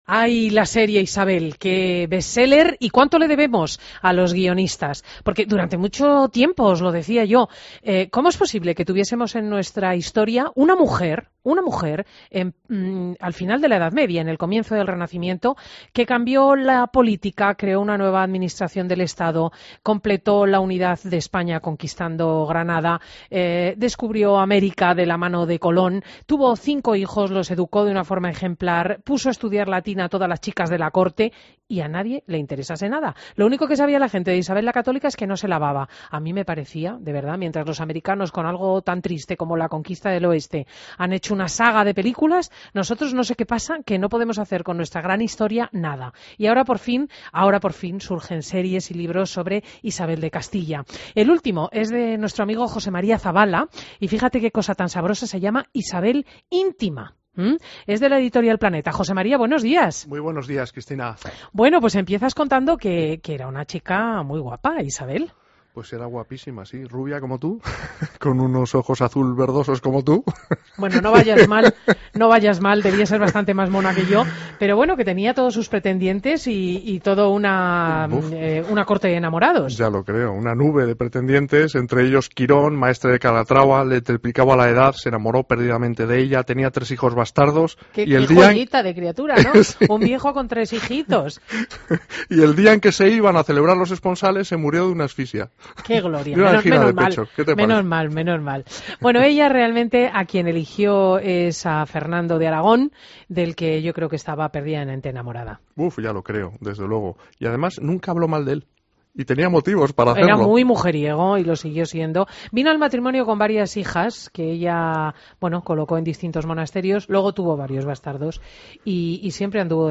AUDIO: Entrevista a José María Zavala, escritor y periodista